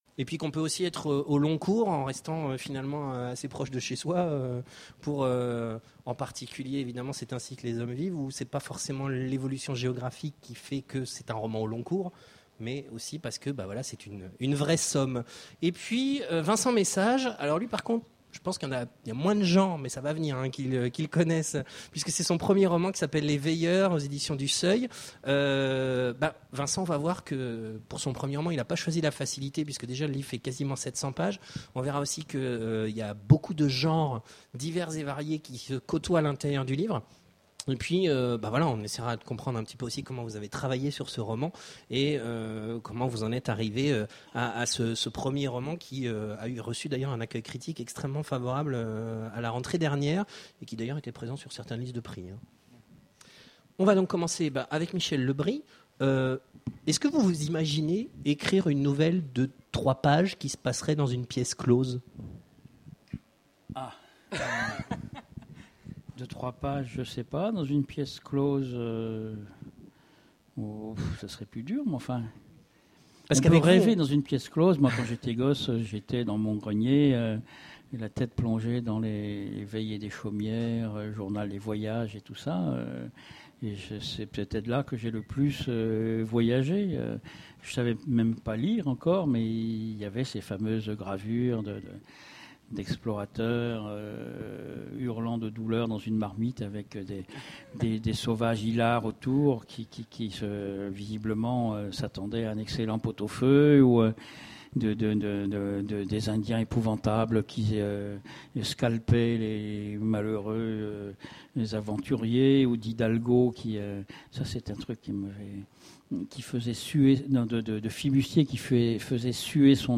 Voici l'enregistrement de la conférence Raconteurs au long cours… Le choix du roman ! aux Imaginales 2010